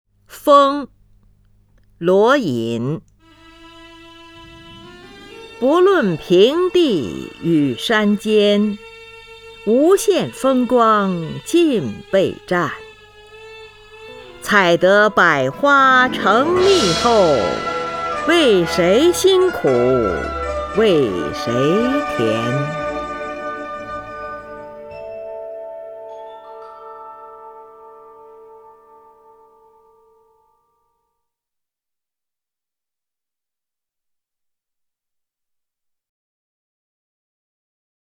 林如朗诵：《蜂》(（唐）罗隐) （唐）罗隐 名家朗诵欣赏林如 语文PLUS
（唐）罗隐 文选 （唐）罗隐： 林如朗诵：《蜂》(（唐）罗隐) / 名家朗诵欣赏 林如